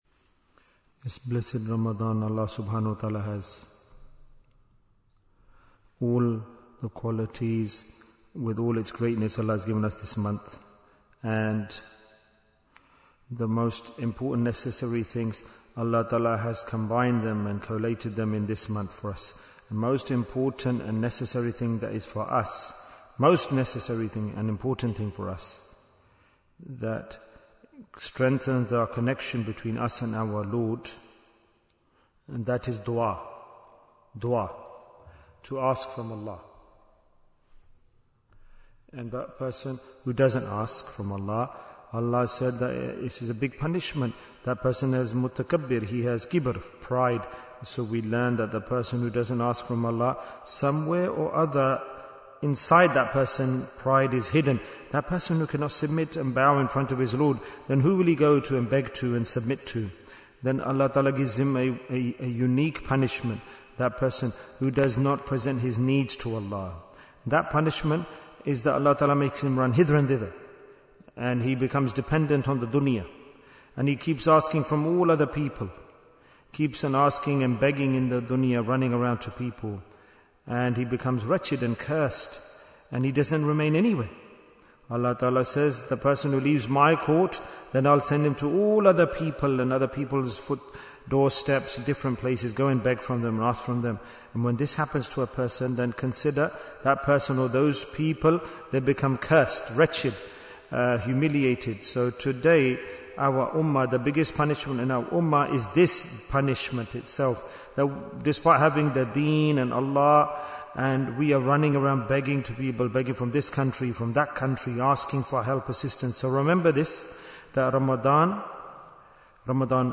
Jewels of Ramadhan 2026 - Episode 6 Bayan, 11 minutes20th February, 2026